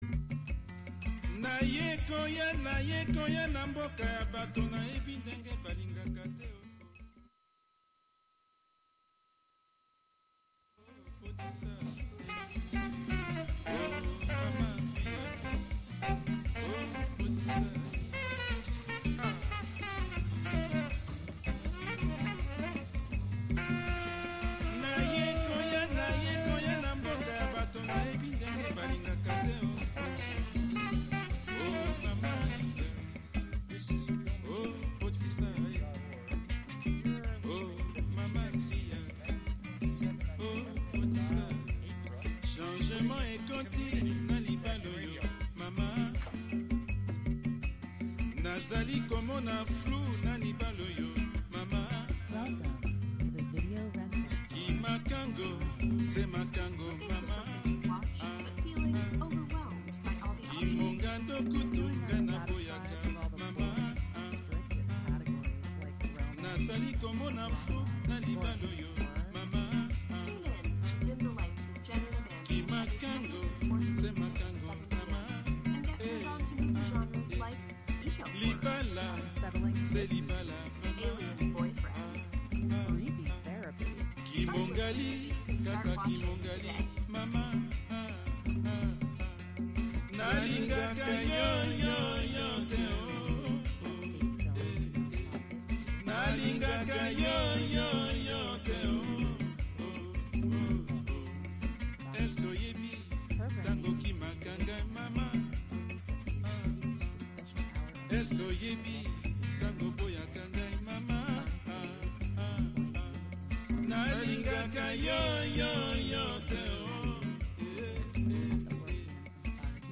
A SPLEN-DID hour of sound and music inspired by the recombinant qualities of food with occasional conversations about milk.